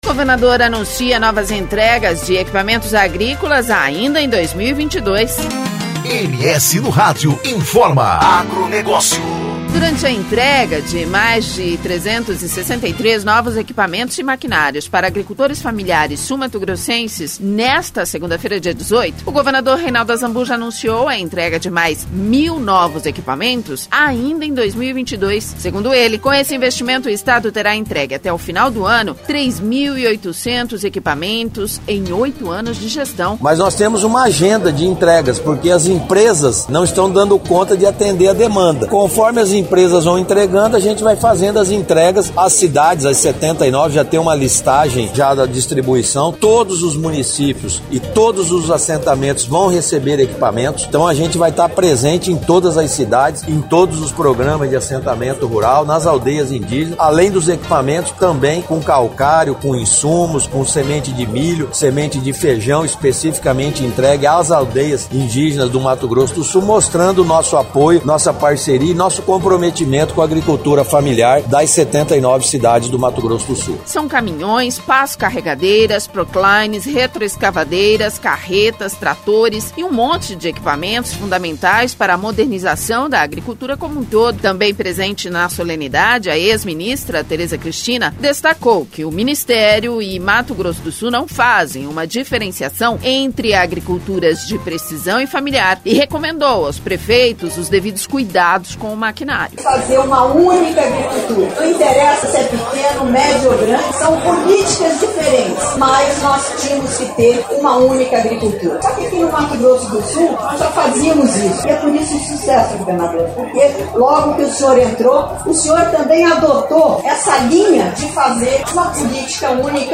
Durante a entrega de mais 363 novos equipamentos e maquinários para agricultores familiares sul-mato-grossenses, nesta segunda-feira, dia 18, o governador Reinaldo Azambuja anunciou a entrega de mais mil novos equipamentos ainda em 2022.